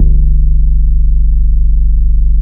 ELEPHANT BAS.wav